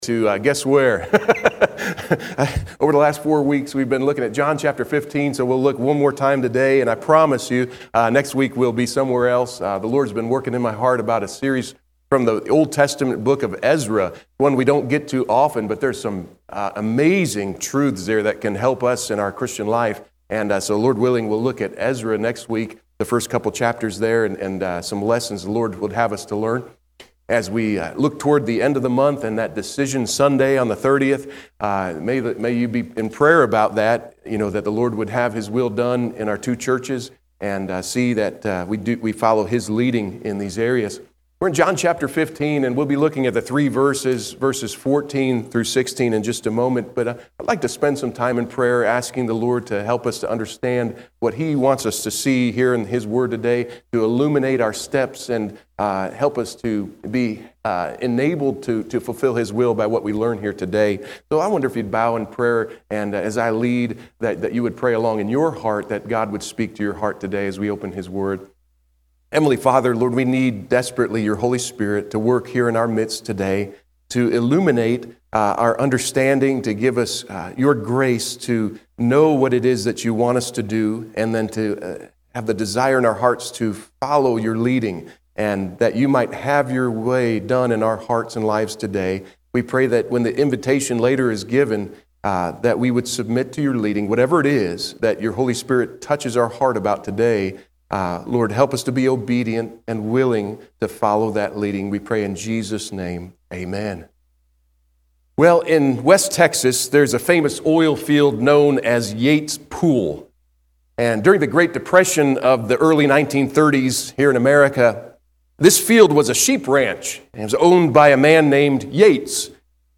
March 9, 2025 AM Service – Loving Christ by Knowing Him